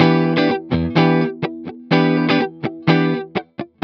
18 GuitarFunky Loop E.wav